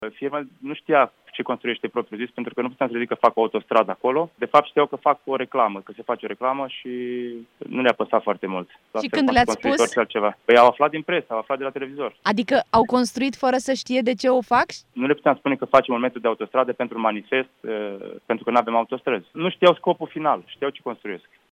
care într-un interviu acordat Europa FM